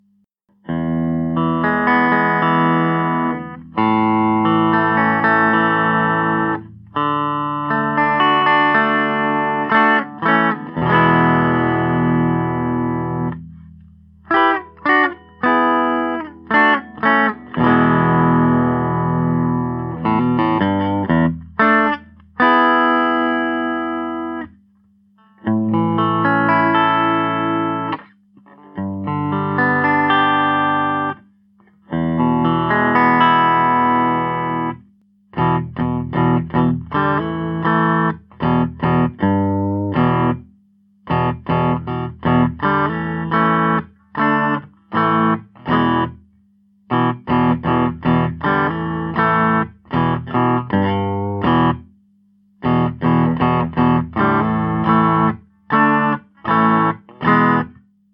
The mark II Cherubim improves on the original Cherubim with tapped tones that are a little fatter and bolder while the full tones are clearer and brighter.
A P-90, with a 50's / 60's style jangly sound and a full P90 sound that rocks hard.
The standard sound samples are of Mark II Cherubims.
Bridge Full      Bridge Tapped